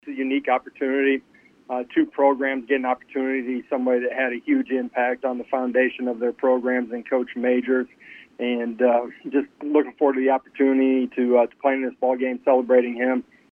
Vols head coach Josh Heupel expressed his excitement about taking part in this celebratory occasion.